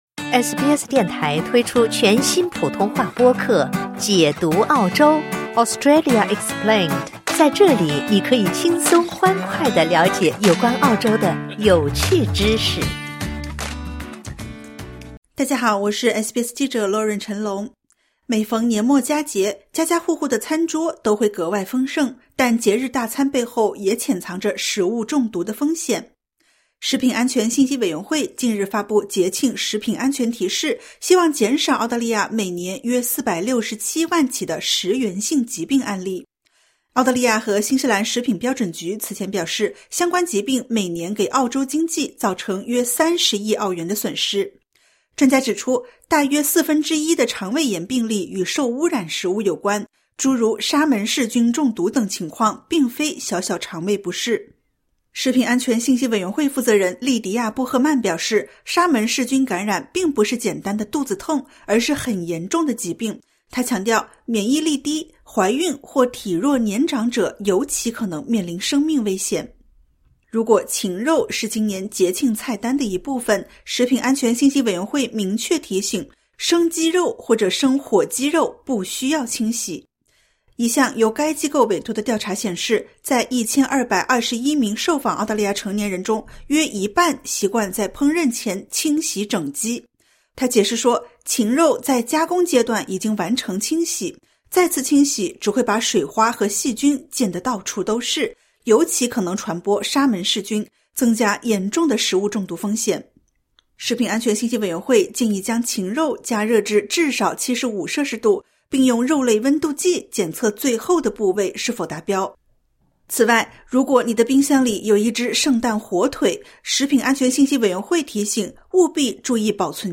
每逢年末佳节，家家户户的餐桌都会格外丰盛，但节日大餐背后也潜藏着食物中毒风险。点击 ▶ 收听完整报道。